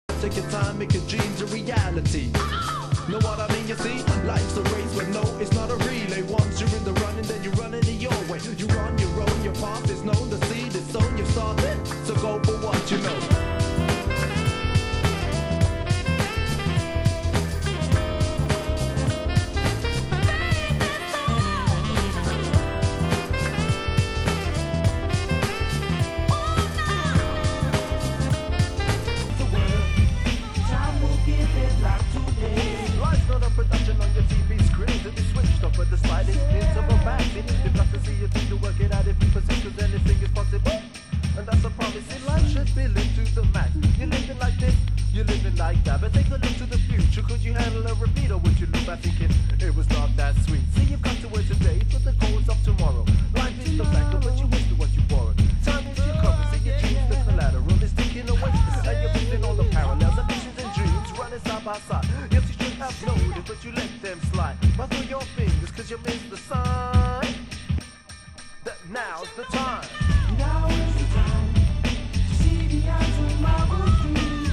Genre: Electronica